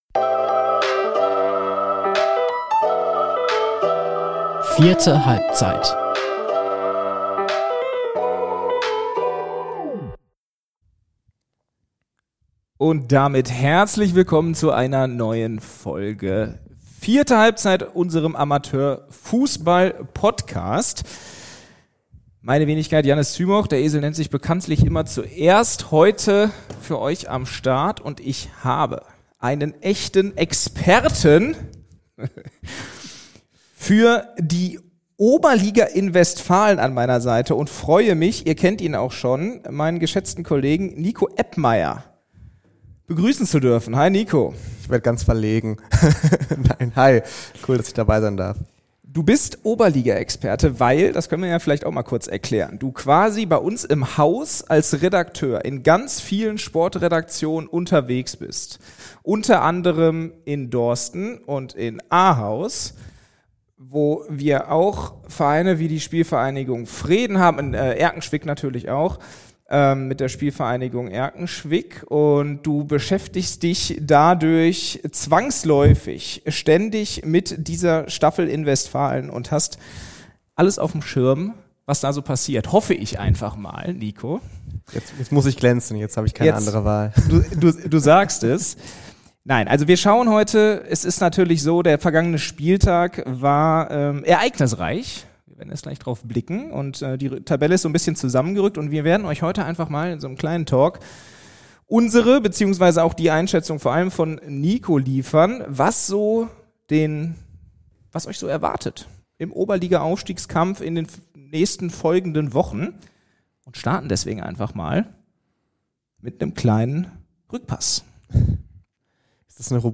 Der Aufstiegskampf in der Oberliga Westfalen spitzt sich zu. In einem Experten-Gespräch ordnet die Dortmunder Lokalsportredaktion die Situation ein - und nennt die Favoriten.